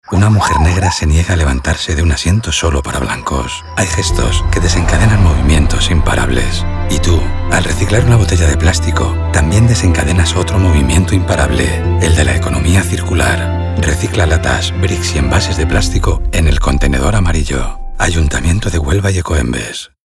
Cuña Radio Botella Plástico - Hª Rosa Parks | Ecoembes
Cuña-20seg-Botella-Rosa-Parks.mp3